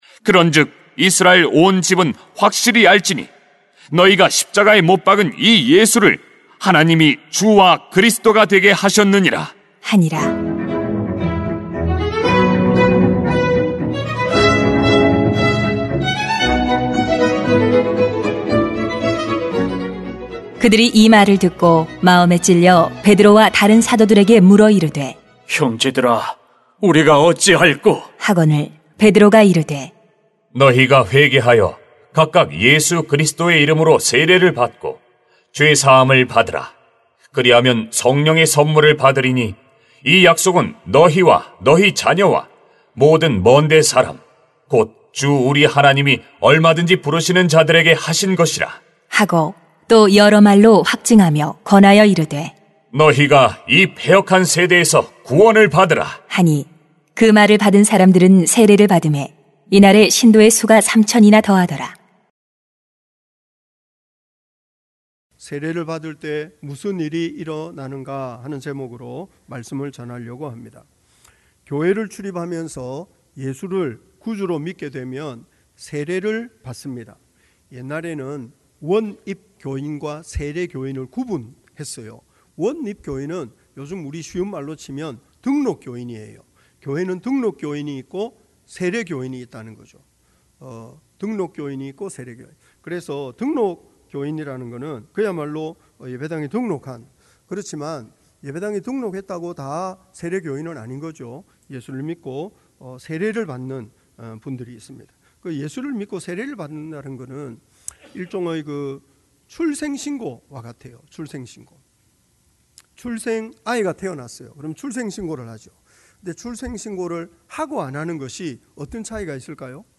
[행 2:36-41] 세례를 받을 때 무슨 일이 일어나는가 > 주일 예배 | 전주제자교회